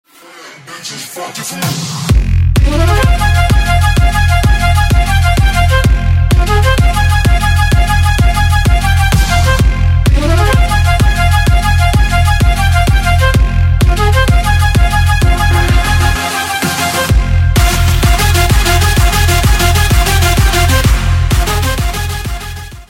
Electronica_4.mp3